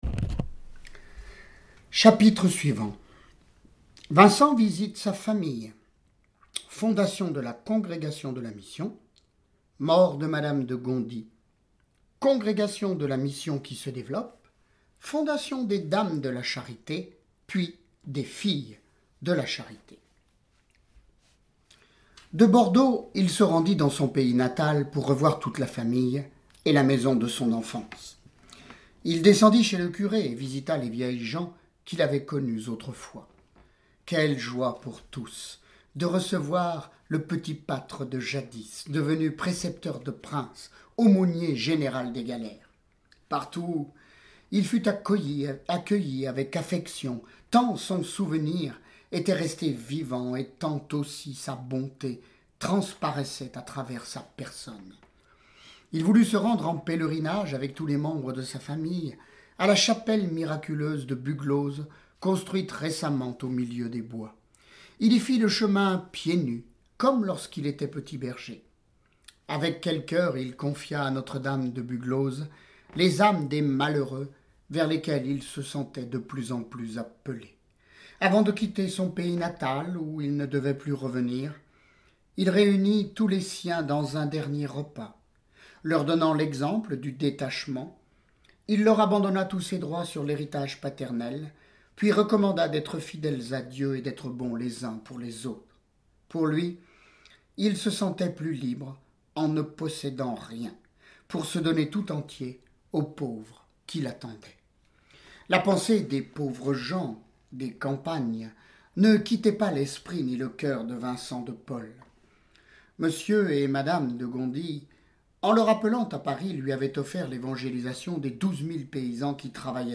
Lecture de vie de Saints et Saintes >> Saint Vincent de Paul